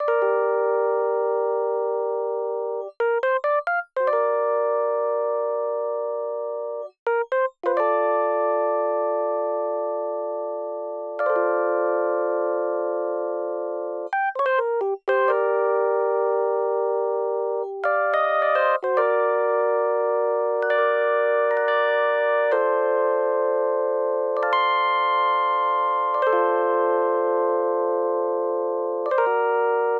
07 rhodes C.wav